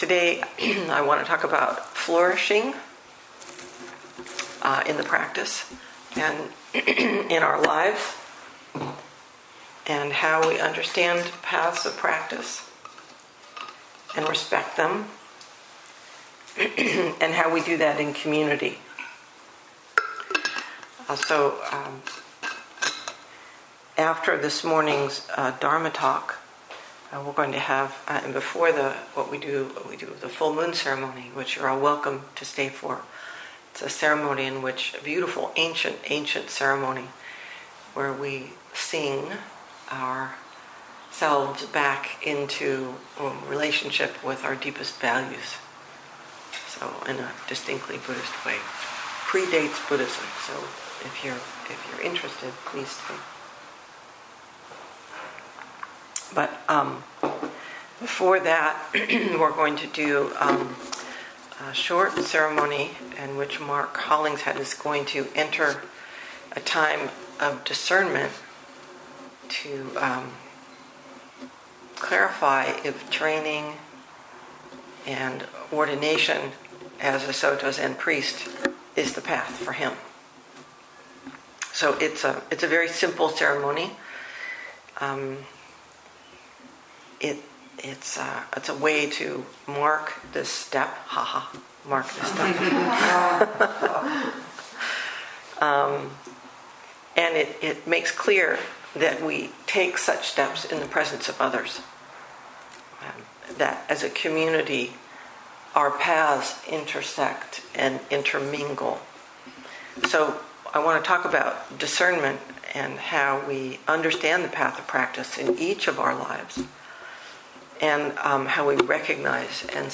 2022 in Dharma Talks